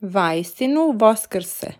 vaistinu voskrse (tap for pronunciation). It means: he truly has resurrected.